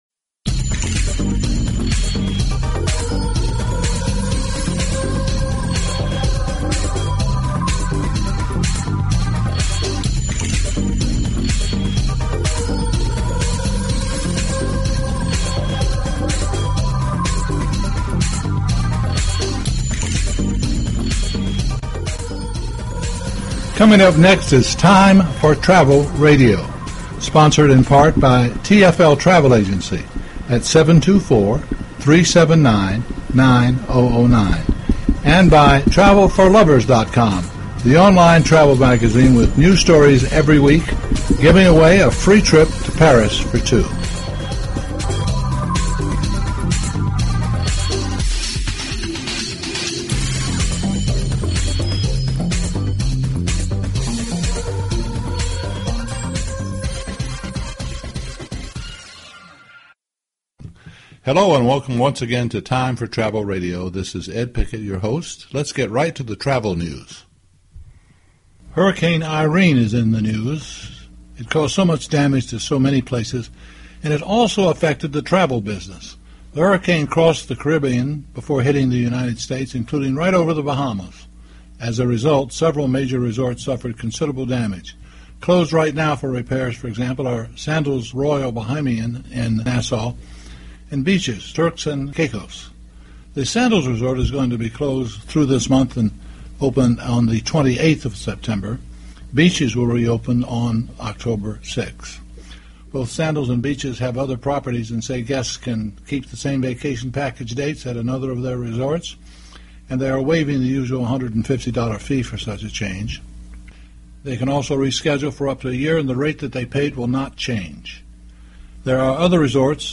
Talk Show Episode, Audio Podcast, Time_for_Travel_Radio and Courtesy of BBS Radio on , show guests , about , categorized as